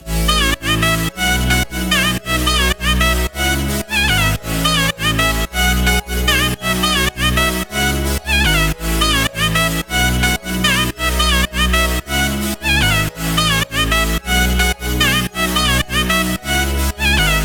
Gully-Loops-Tabahi-Drop-Loop-BPM-110-B-Maj.wav